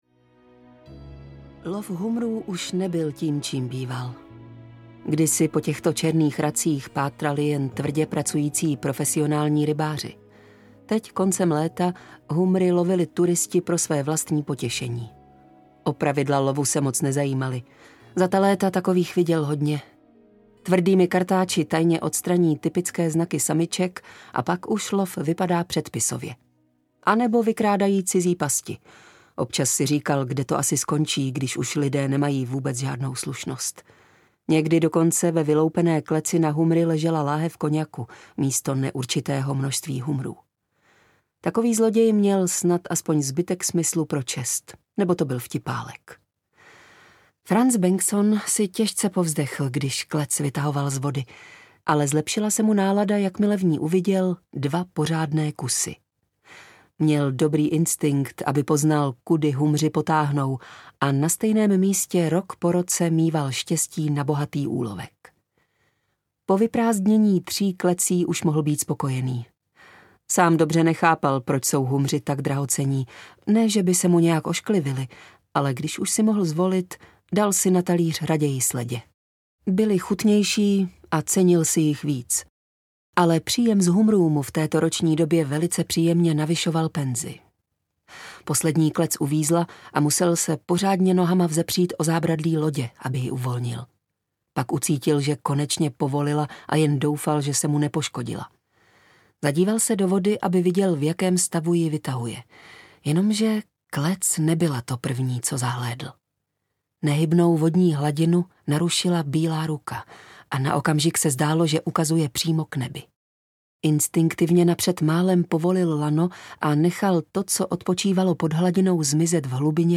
Kameník audiokniha
Ukázka z knihy
kamenik-audiokniha